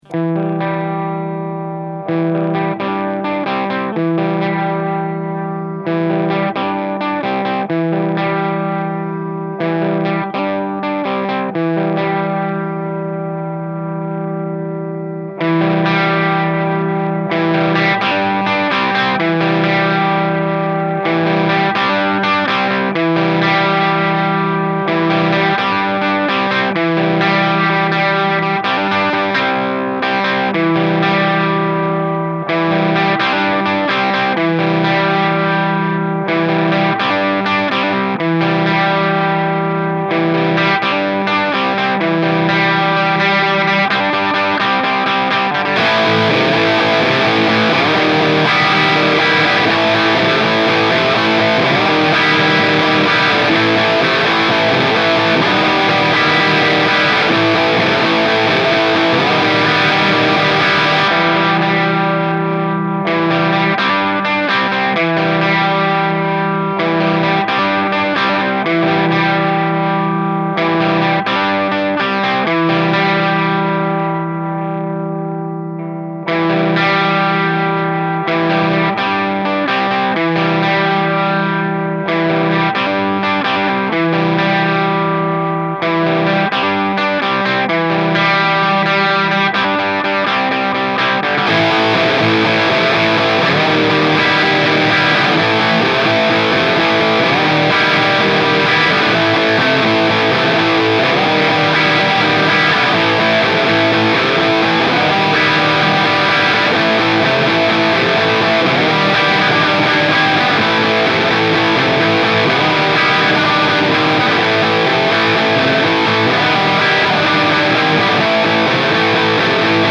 EDIT : Pour les oreilles, un petit sample comparatif : d'un côté, nous avons une prise de son façon "débutant home-studiste", avec un cab 1x8" ouvert en Eminence 875L repris par un Sennheiser e606 décentré de quelques centimètres par rapport au centre du HP ; de l'autre, le même morceau, joué avec le même préampli et la même gratte, en direct dans le PC avec Logic 9 et Recabinet en simu HP :
la version Recabinet (à gauche un 4x12 Mesa en V30 repris par un SM57 centré, à droite un 4x12 Marshall en greenbacks repris par un MD421 décentré avec un angle de 45°, et au centre un 4x12 Orange en V30 repris par un U87 décentré)
A noter que plusieurs mois séparent les 2 prises, donc les réglages sont globalement identiques, mais ont pu changer un peu, ce n'est pas un test scientifique, mais ça donne une idée du résultat possible quand on n'y connait rien (et même moins)
sample_mix_recabinet.mp3